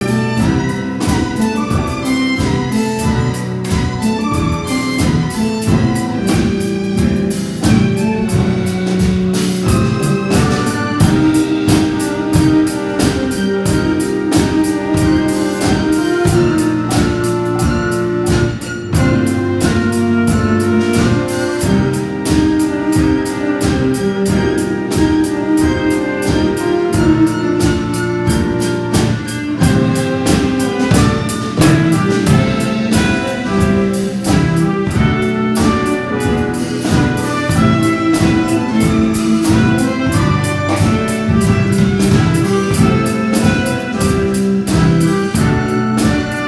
そして、ピアノ、チェロ、バイオリン、トランペット、サックス、パーカッションで、たくさんの曲を披露してくださいました。